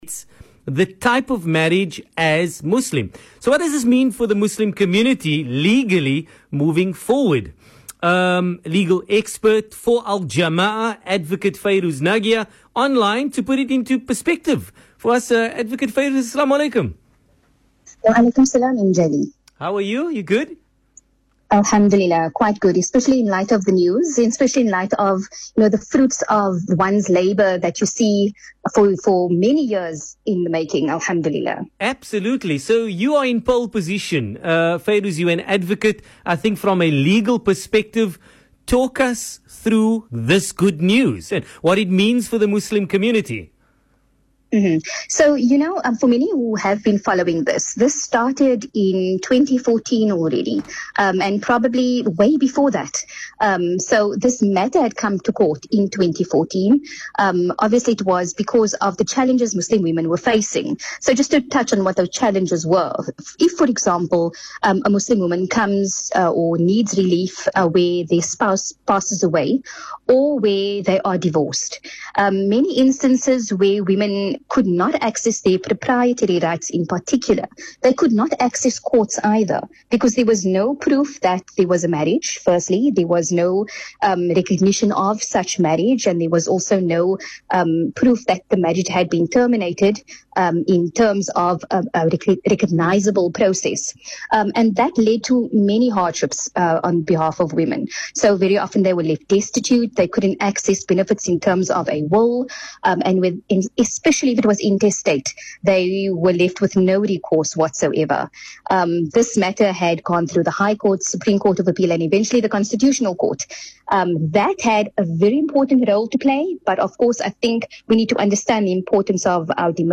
*Feel free to listen further as she explains the legal implications.